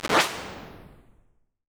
magic_dust_a.wav